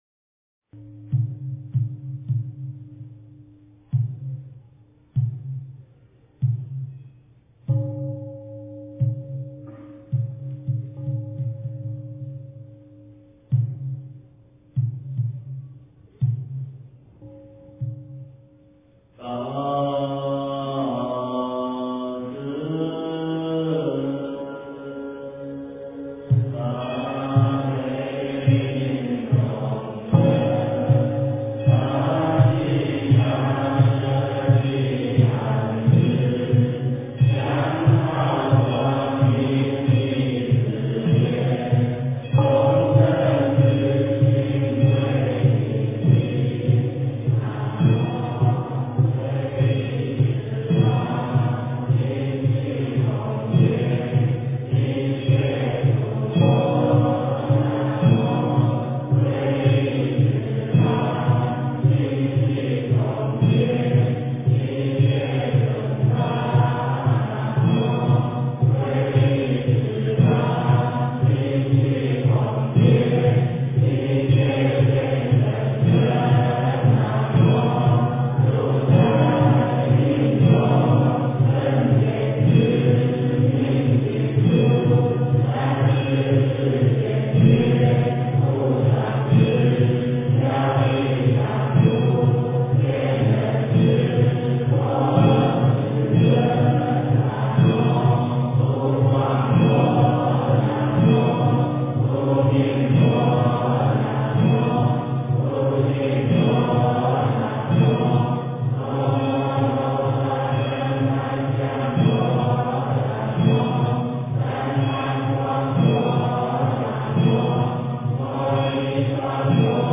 八十八佛晚课--承天禅寺 经忏 八十八佛晚课--承天禅寺 点我： 标签: 佛音 经忏 佛教音乐 返回列表 上一篇： 普佛(代晚课)(上)--僧团 下一篇： 三时系念--悟道法师 相关文章 观世音菩萨普门品--陕西歌舞剧院民乐队 观世音菩萨普门品--陕西歌舞剧院民乐队...